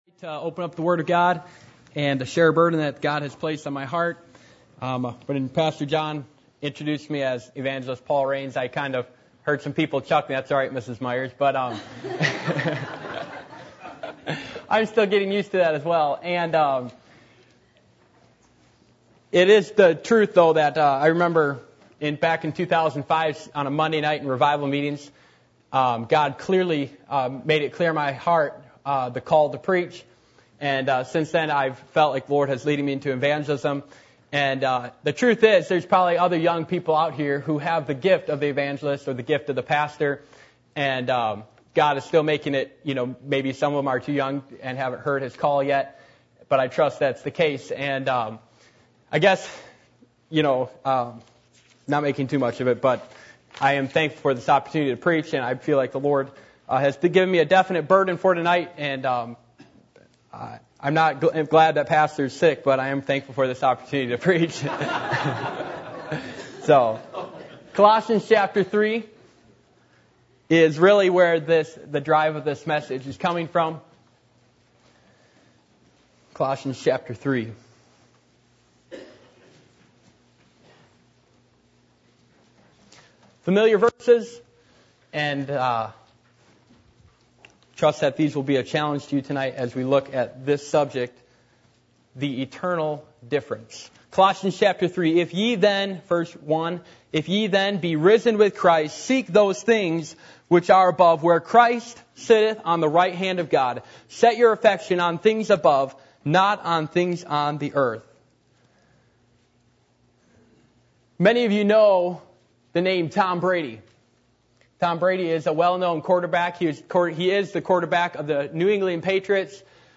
Passage: Colossians 3:1-2 Service Type: Sunday Evening